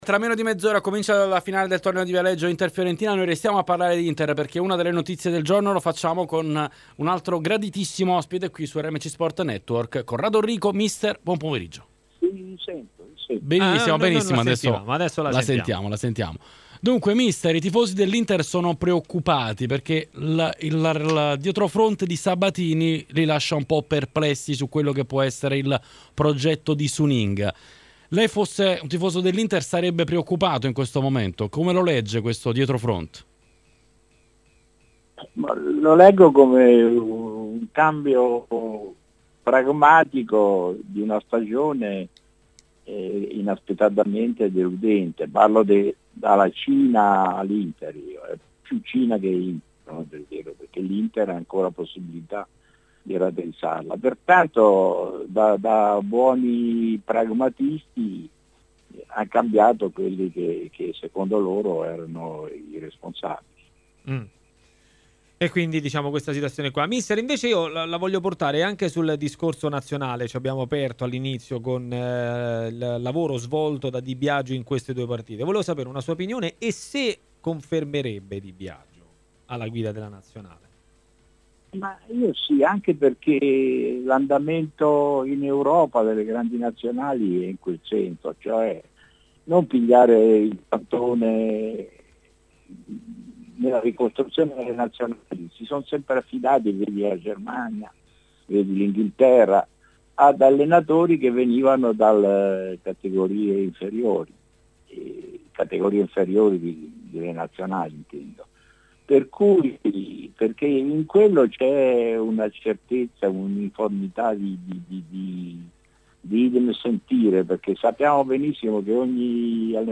Dalle dimissioni di Sabatini alla corsa Scudetto, passando per la nazionale italiana. Questi i temi toccati da mister Corrado Orrico, intervenuto su RMC Sport a 'Maracanà'.
Corrado Orrico intervistato